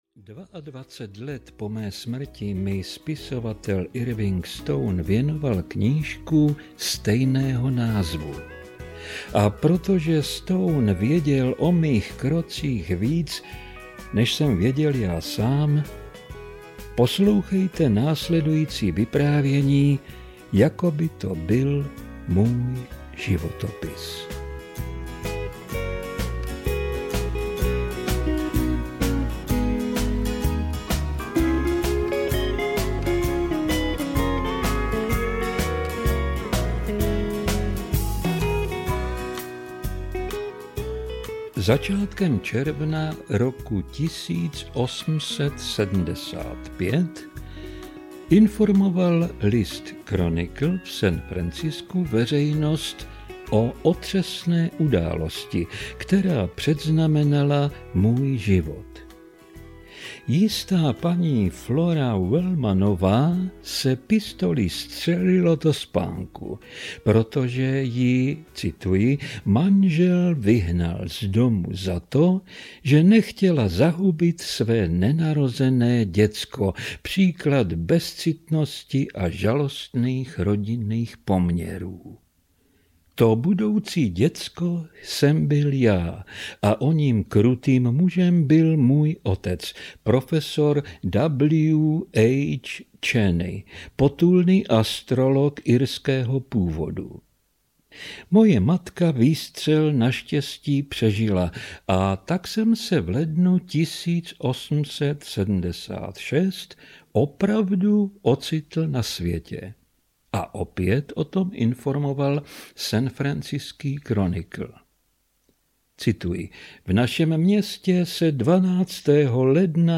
Putování námořníka na koni audiokniha
Ukázka z knihy